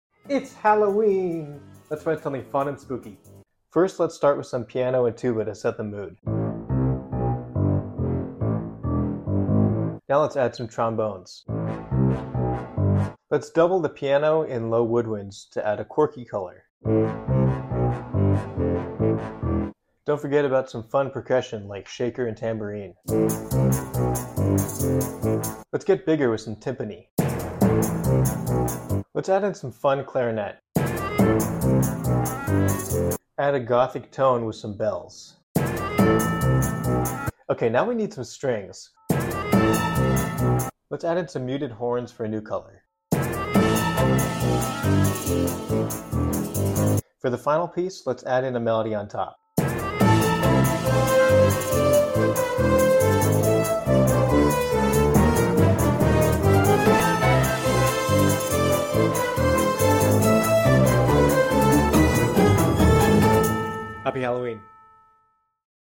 Let’s write some fun Halloween Music together!